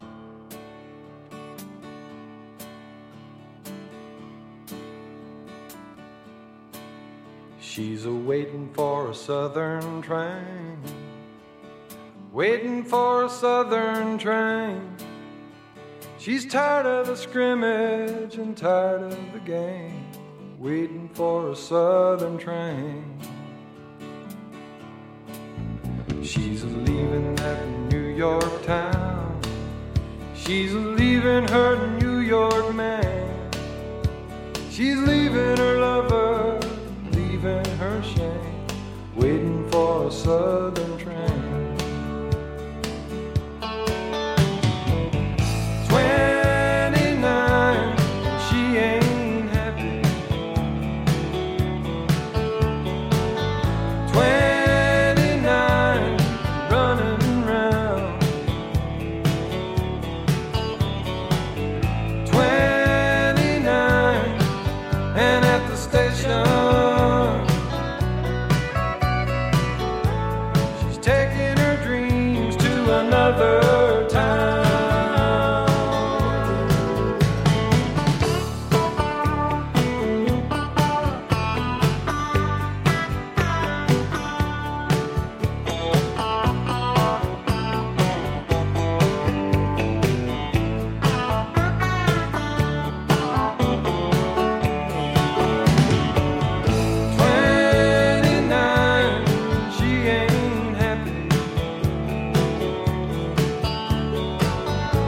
sa voix grave et merveilleuse